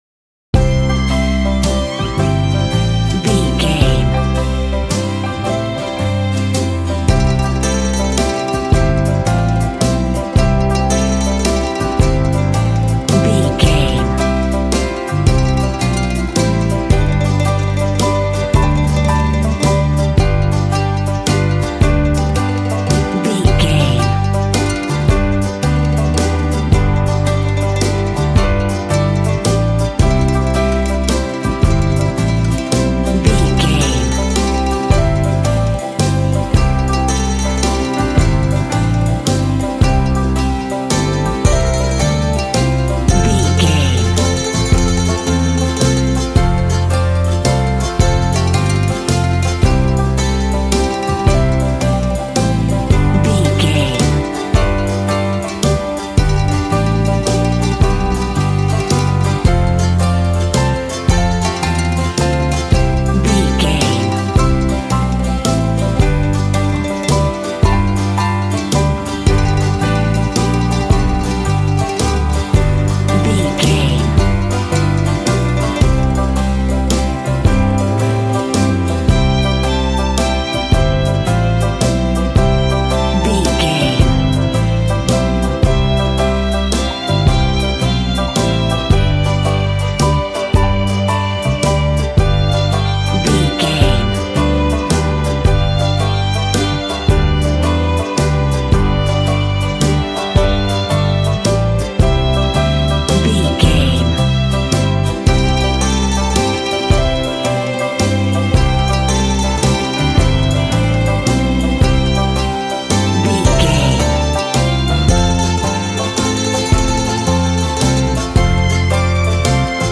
A wedding dance at a traditional country music gathering.
Ionian/Major
Fast
fun
bouncy
double bass
drums
acoustic guitar